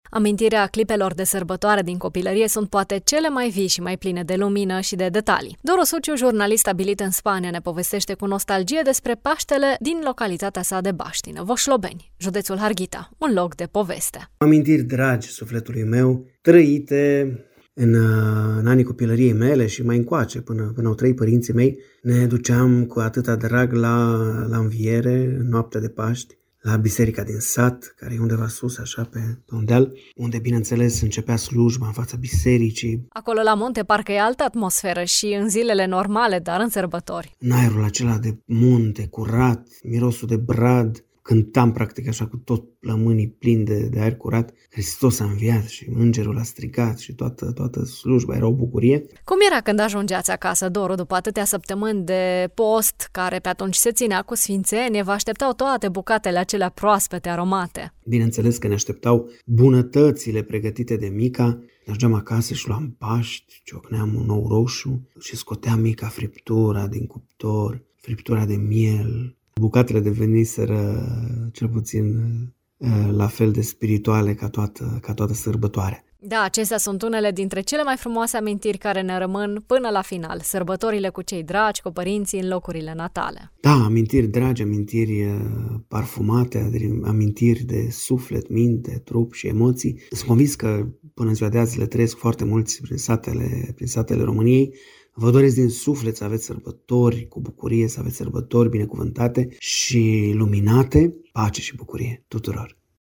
În preajma sărbătorilor, românii stabiliți departe de casă, resimt cel mai acut dorul de tradiții și de cei dragi. Bucate aromate, aer rece de brad și sfințenie…acestea sunt amintirile unui harghitean plecat în cealaltă parte a Europei. Interviu